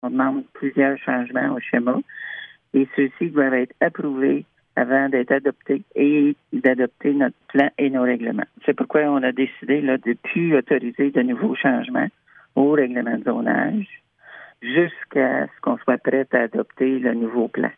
De plus, Lac-Sainte-Marie a adressé plusieurs demandes de changement auprès de la MRC en lien avec le nouveau schéma d’aménagement et de développement et ceux-ci doivent être approuvés avant que la municipalité puisse adopter les changements, comme l’indique la mairesse Cheryl Sage-Christensen :